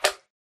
Sound / Minecraft / mob / magmacube / small1.ogg